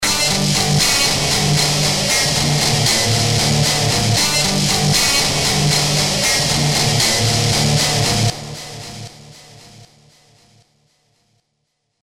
Here are two sample files recorded using Hammer and some effect patches included in the Hammer ReFill... Check them out!
Hammer and Guitars
Hammer_Guitar.mp3